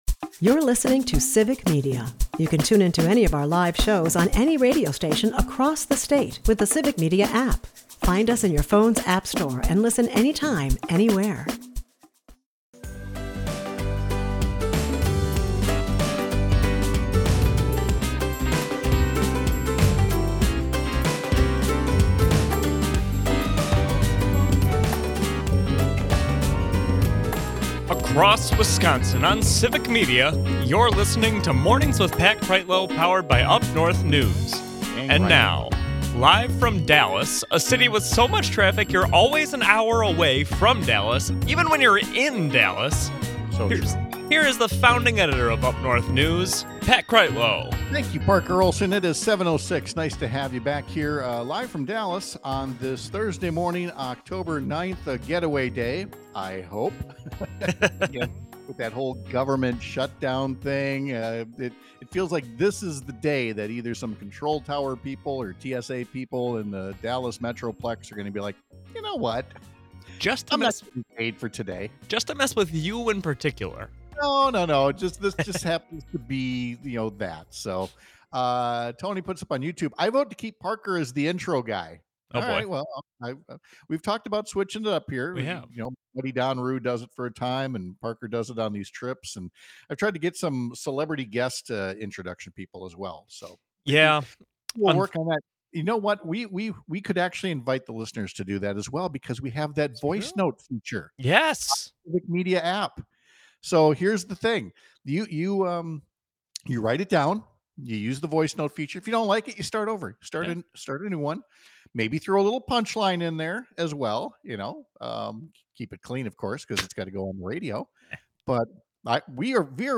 Guests: Mark Pocan